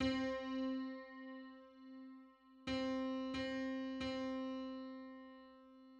Semicomma_on_C.mid.mp3